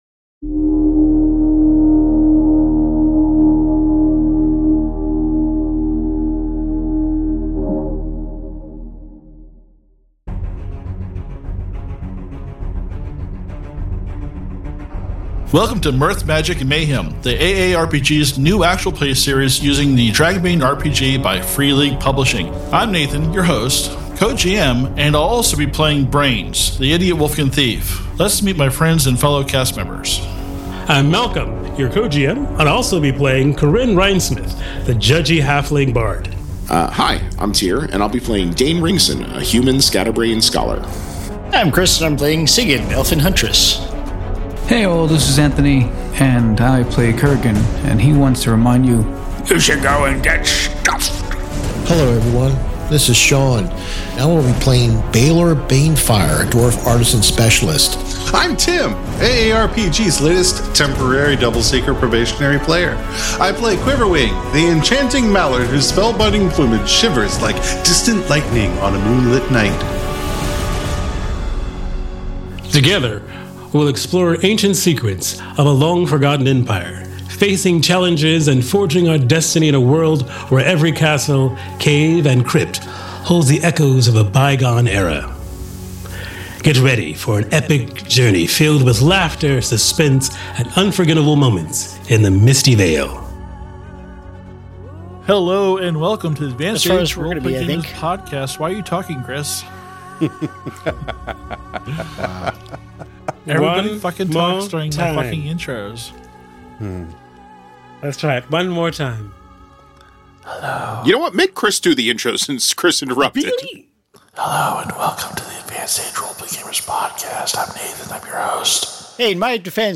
Step into the rich tapestry of Dragonbane RPG as our actual play podcast unfolds in the Misty Vale, a region steeped in history as the heart of the Dragon Empire thousands of years ago.
Also and interlude with Quiverwing and Miserable Beast. You may have questions, but I don't have answers...yet. Also, this episode uses a lot of binaural effects that will sound better on headphones.